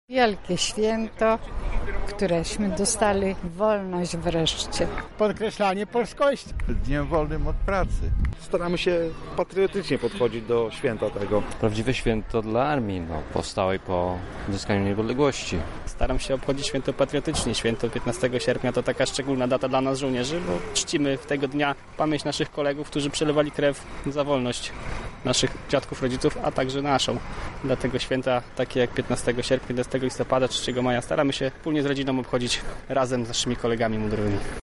Czym jest dla mieszkańców to święto i jak je obchodzą zapytał nasz reporter: